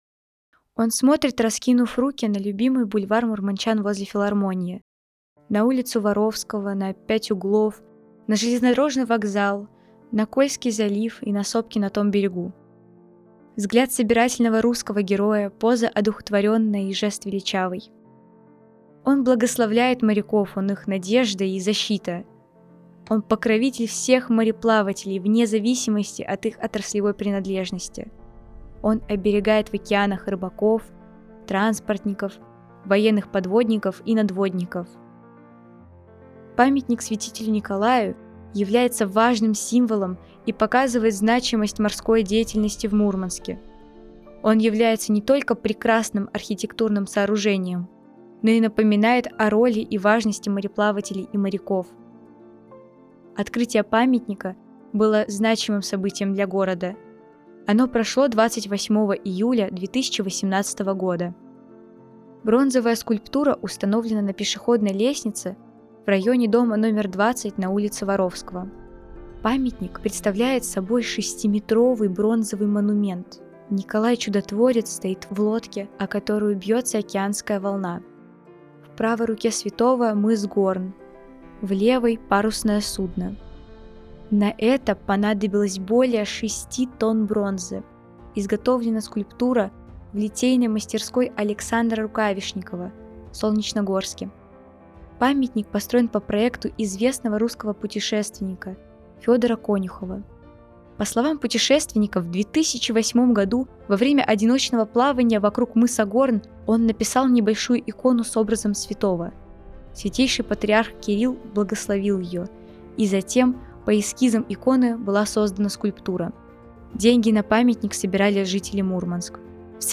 Представляем новую аудиоэкскурсию, подготовленную волонтерами библиотеки в рамках туристического проекта «51 история города М»